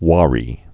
(wärē)